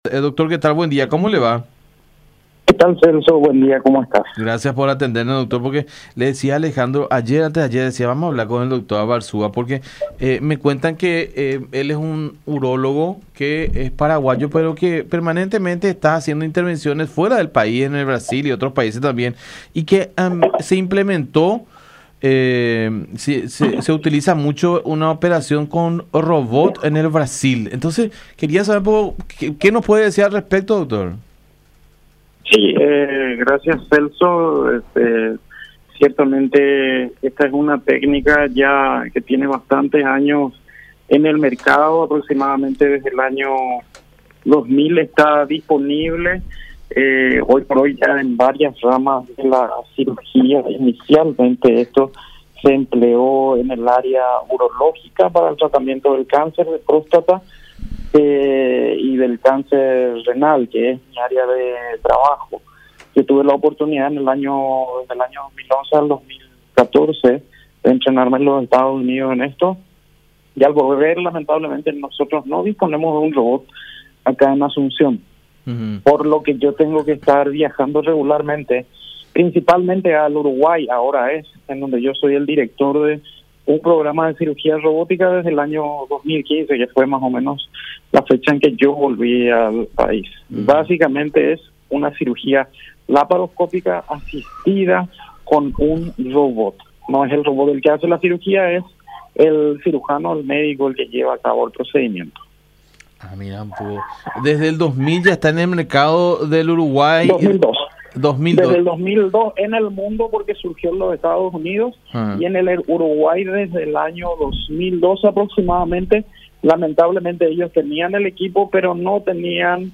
En diálogo con La Unión, el profesional dijo que las cirugías robóticas están salvando vidas de hombres que padecen cáncer de próstata en países vecinos, pero lamentablemente de estos equipos no se disponen en Paraguay.